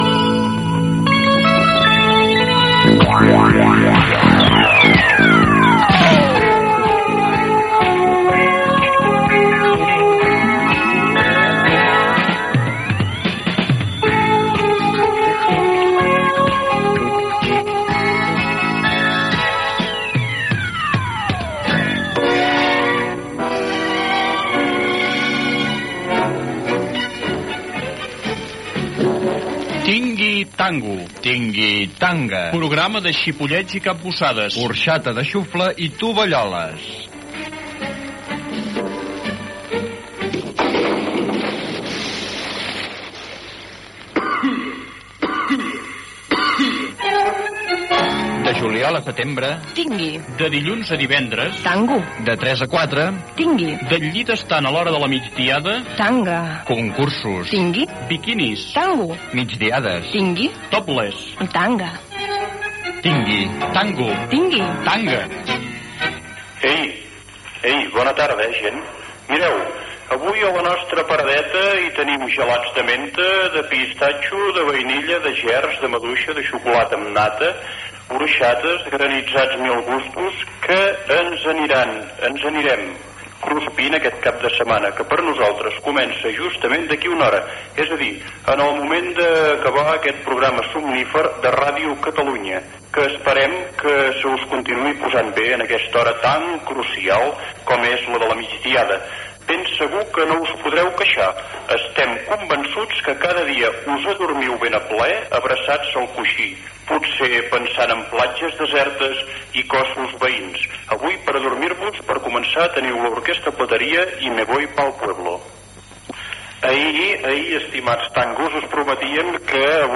932e04478eb61eed3815a11a5c28a7b7a026e60d.mp3 Títol Ràdio Catalunya Emissora Ràdio Catalunya Cadena Cadena 13 Titularitat Privada local Nom programa Tingui Tango, Tingui Tanga Descripció Sintonia de l'emissora, careta del programa, presentació, entrevista als integrans del grup musical Orgue de Gats.